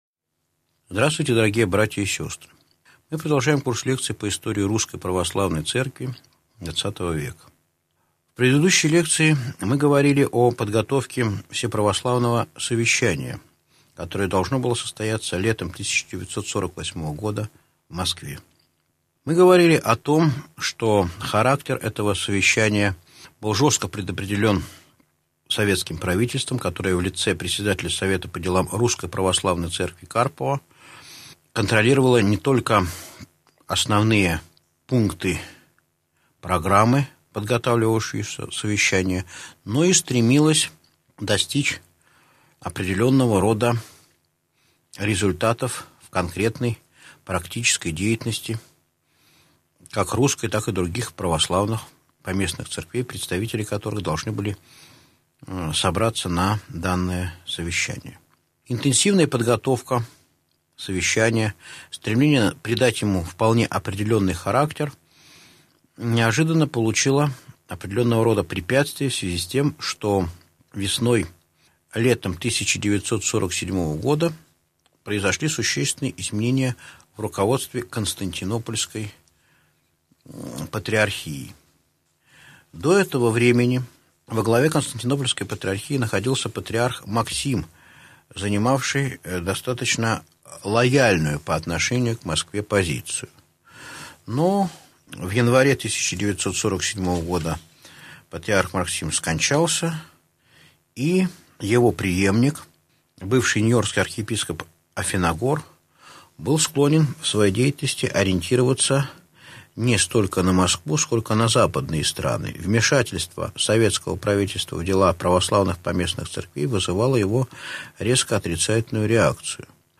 Аудиокнига Лекция 25.